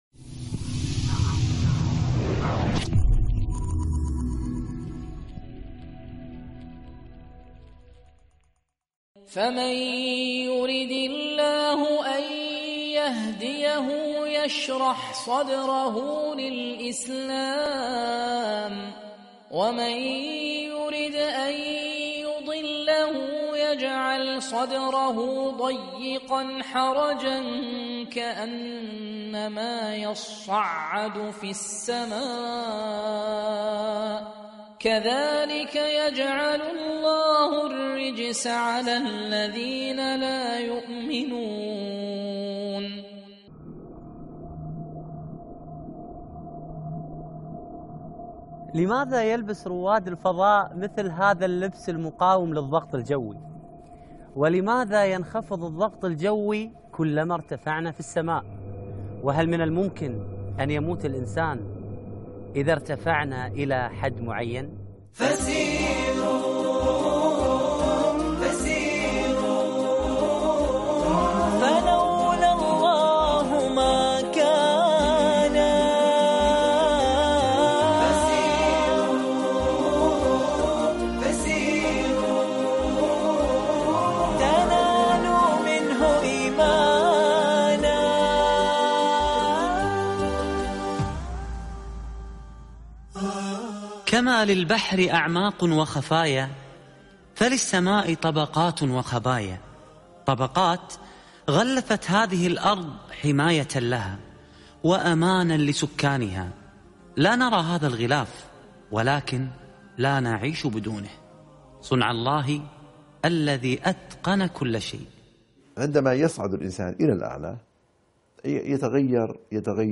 الغلاف الجوي - ح9 - فسيروا - القاريء فهد الكندري